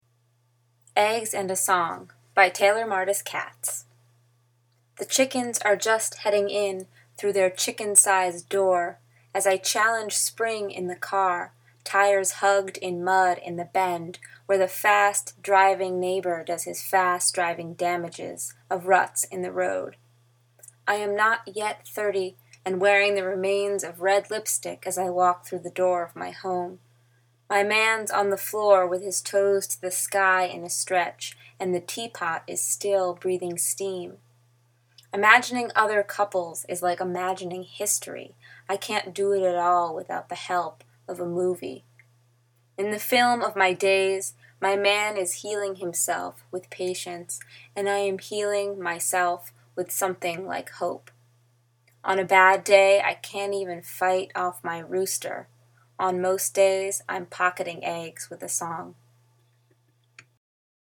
“Eggs and a Song” (poem out loud) (mine!).